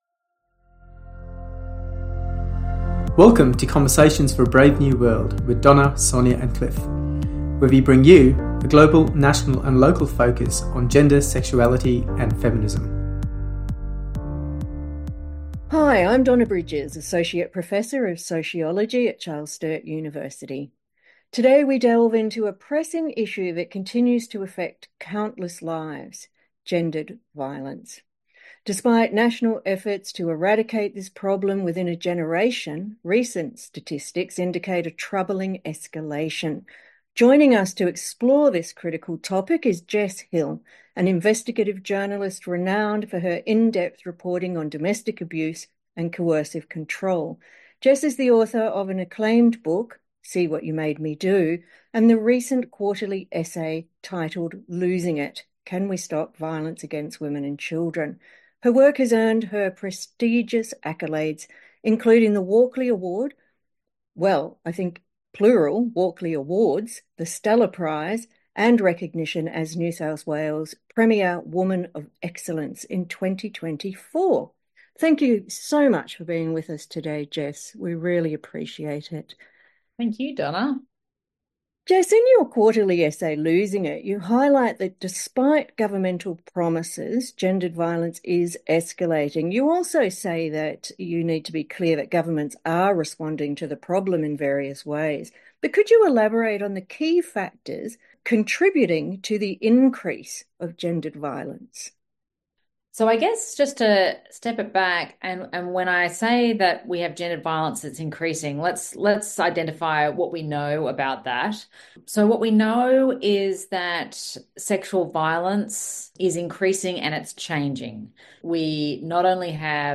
‘Losing It’: A Conversation with Jess Hill on Ending Gendered Violence - 2MCE Community Radio